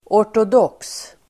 Uttal: [år_tod'åk:s]
ortodox.mp3